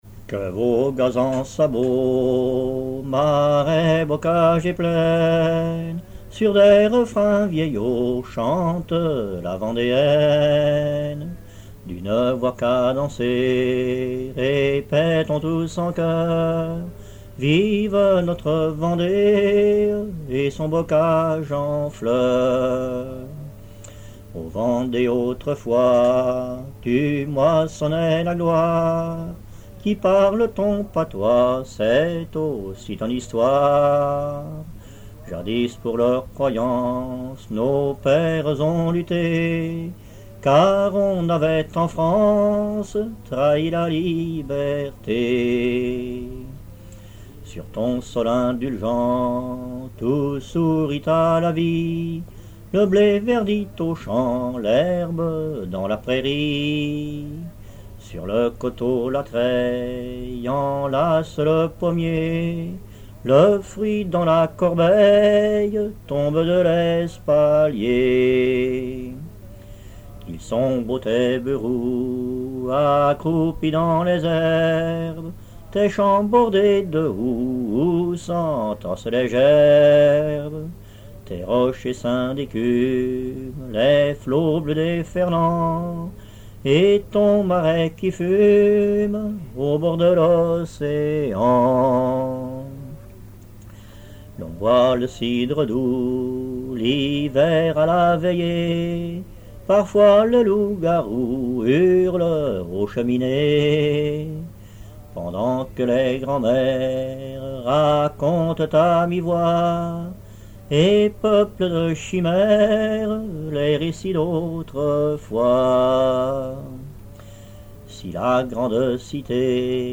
Chansons traditionnelle
Pièce musicale inédite